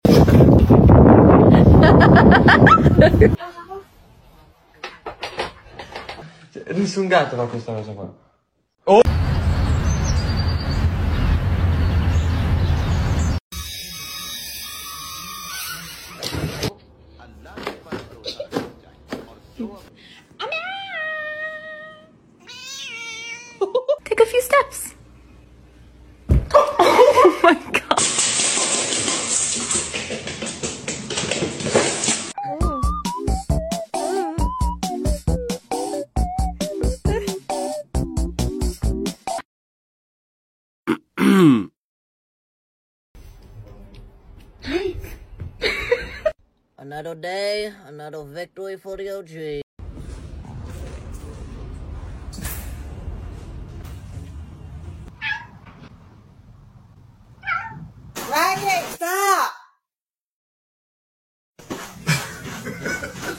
Crazy and Funny Orange Cats sound effects free download